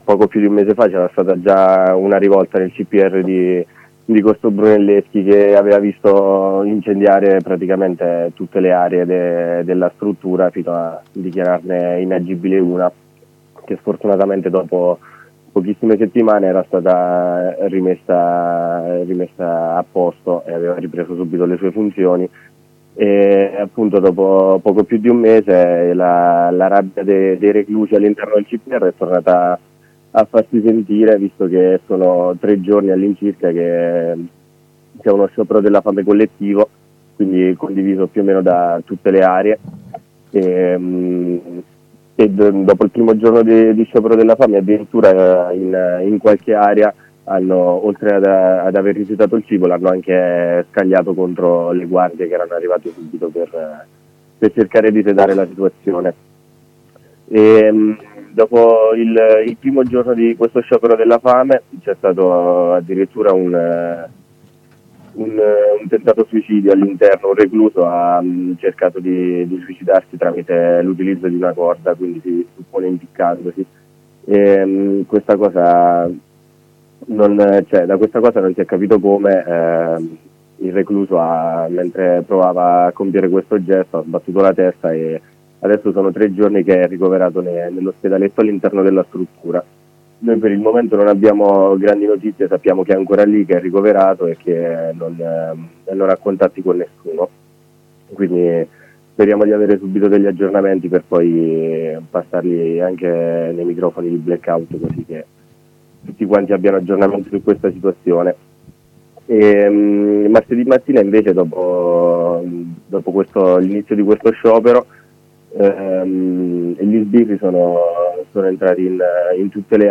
Il racconto di due compagni in contatto con i reclusi del CPR di Roma e del CPR di Torino